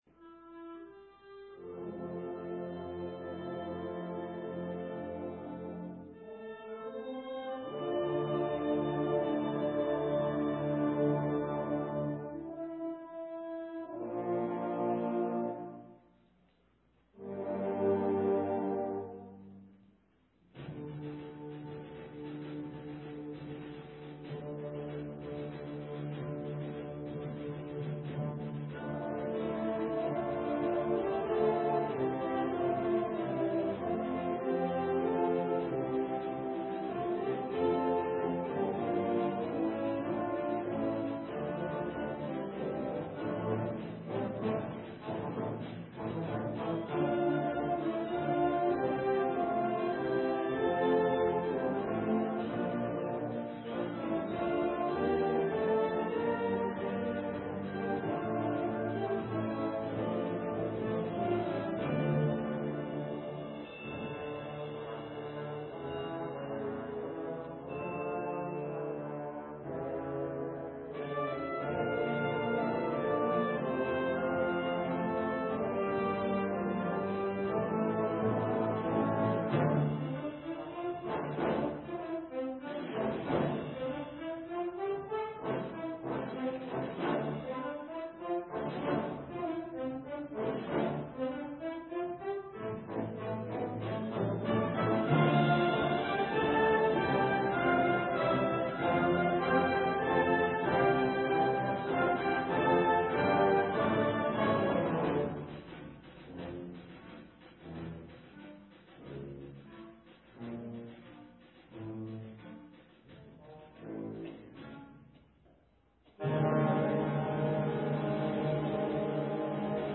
An Autumn Celebration – Fall Concert 10/21/2023 - Sun City Concert Band
An Autumn Celebration – Fall Concert 10/21/2023